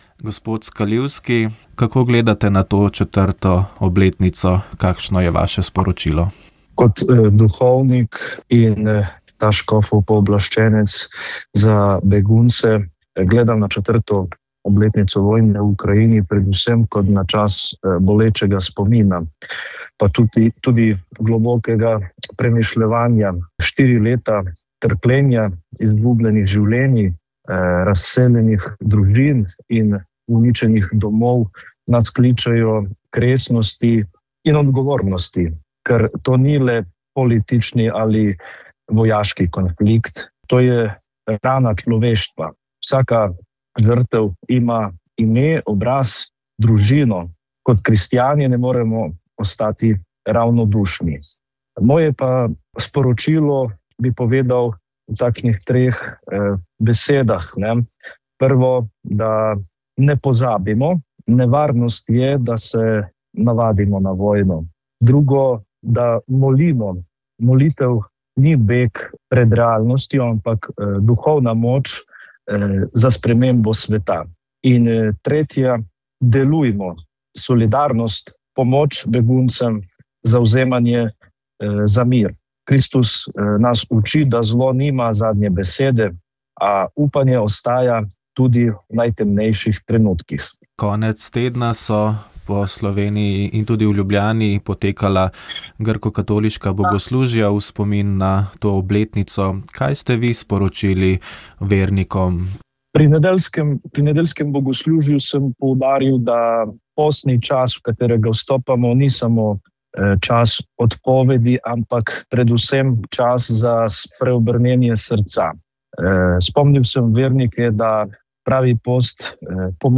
Na naša vprašanja je odgovarjal generalni direktor direktorata za informacijsko družbo, dr. Uroš Svete.